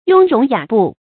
雍容雅步 注音： ㄩㄥ ㄖㄨㄙˊ ㄧㄚˇ ㄅㄨˋ 讀音讀法： 意思解釋： 謂神態從容，舉止斯文。